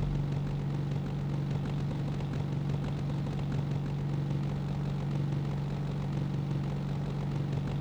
Index of /server/sound/vehicles/lwcars/delta
idle.wav